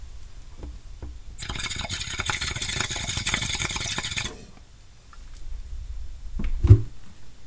噪音和合成物 " 啪
描述：我在手指上折断了一条鲷鱼。
标签： 一声巨响 回声 实际上 响亮 噪声 流行音乐 波普 混响 捕捉 声音
声道立体声